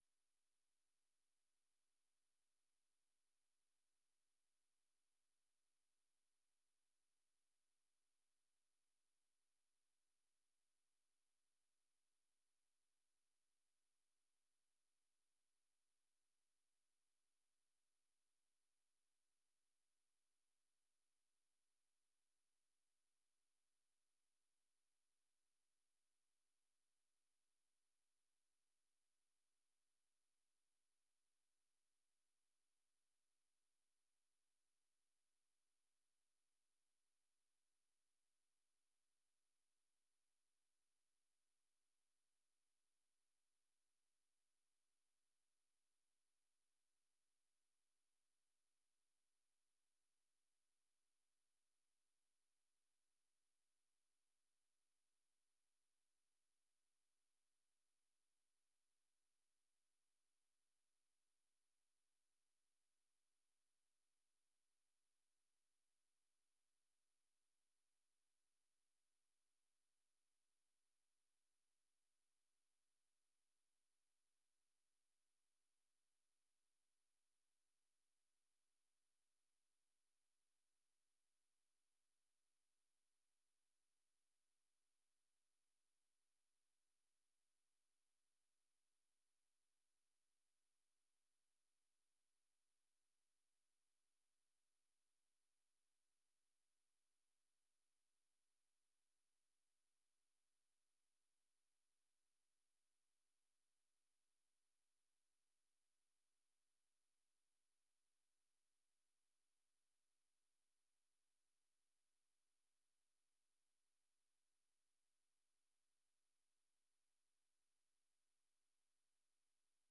Vergadering van de commissie Samenlevingszaken op dinsdag 19 september 2023, om 19.30 uur.